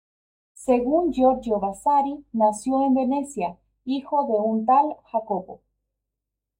Read more Adj Pron Frequency A1 Pronounced as (IPA) /ˈtal/ Etymology Inherited from Latin tālis Cognate with French tel In summary Inherited from Old Spanish tal (“such”), from Latin tālis.